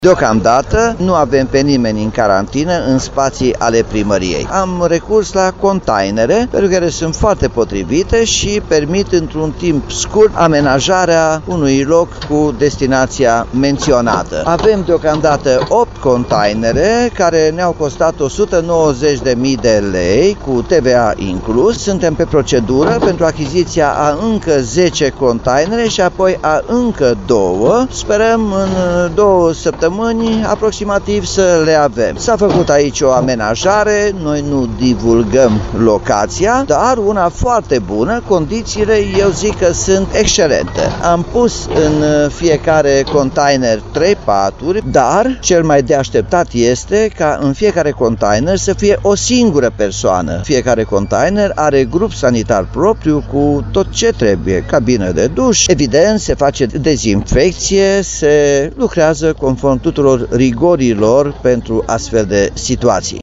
Primarul Timișoarei, Nicolae Robu.